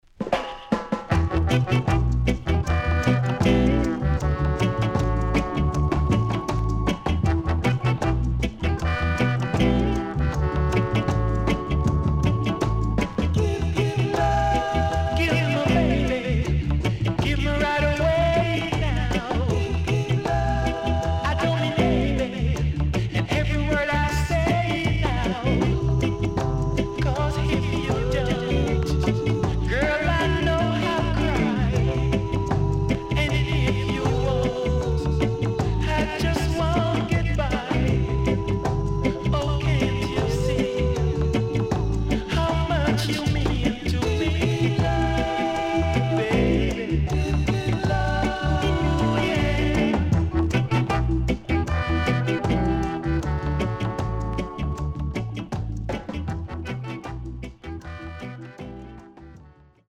哀愁漂うEarly Reggaeの名曲の数々を収録した名盤
SIDE A:全体的にチリプチノイズ入ります。